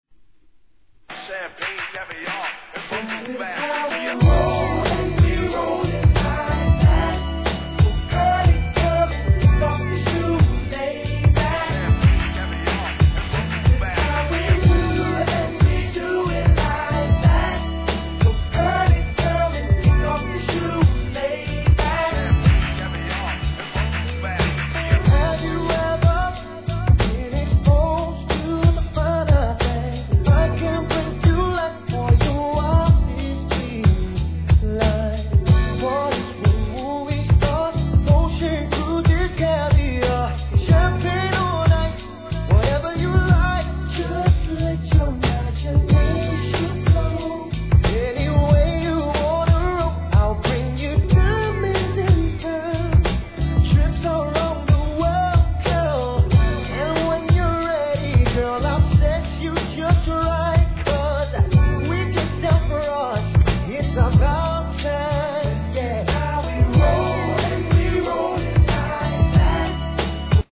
HIP HOP/R&B
ラティーノ5人組のヴォーカル・グループ!